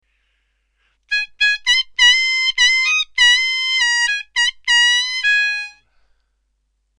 diatonic harmonica